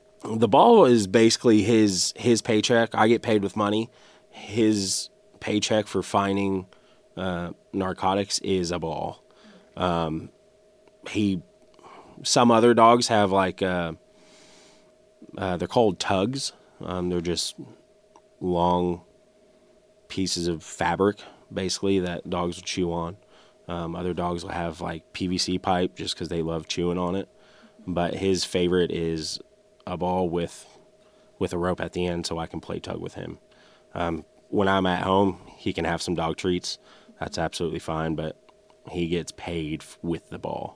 Howell County, MO. – Following the current project of Back the Blue K9 Edition, we had the Howell County Sheriff’s Department come in for an interview to describe the day in the life of a K9, specifically one that has only been on the job for a week.